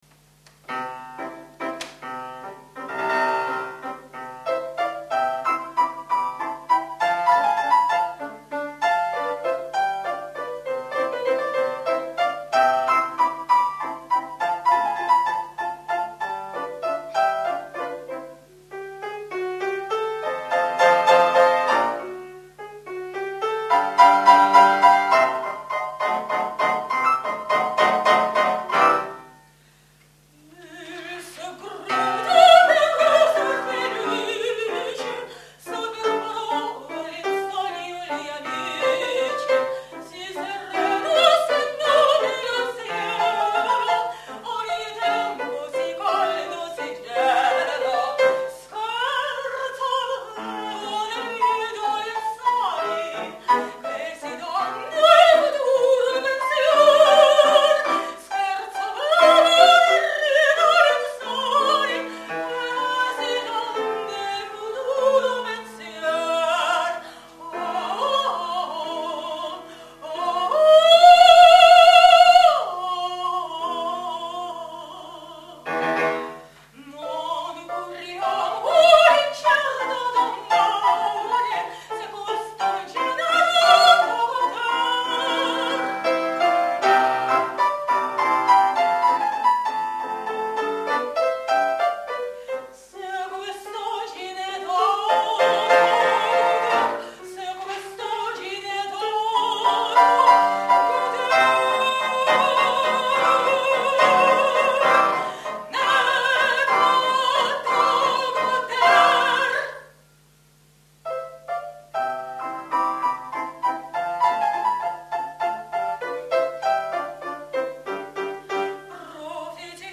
Застольная песня Орсини из оперы "Лукреция Борджиа" Гаэтано Доницетти
меццо-сопрано